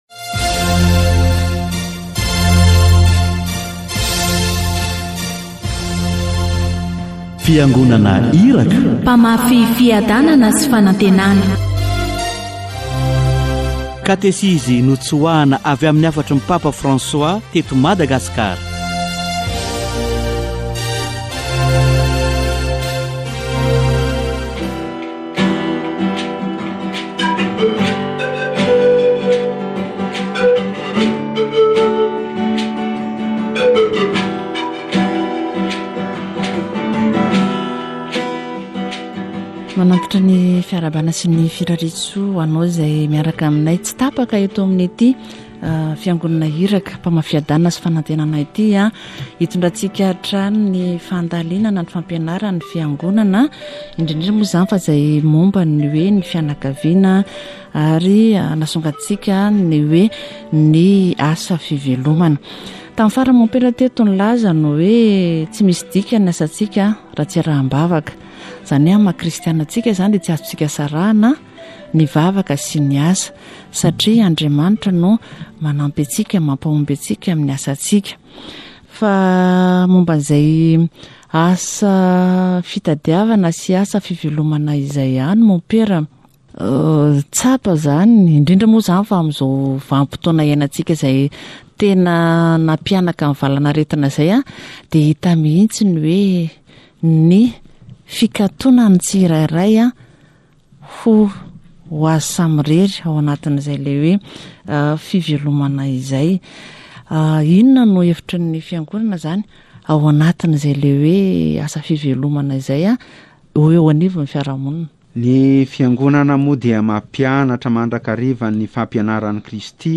We now see that the "lockdown" resulting from the fight against Covid-19, reveals the gaps between the People and the Leaders, the rich and the poor. Many are so closed to themselves and their families that they are unable to develop their careers. Catechesis on livelihood